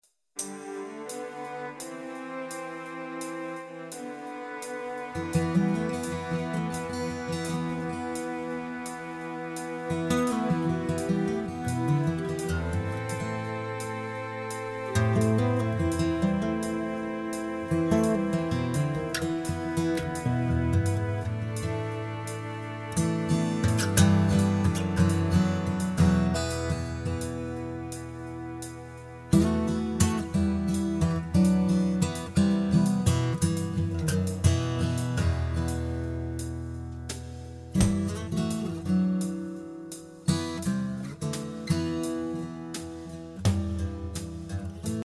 Listen to the instrumental track.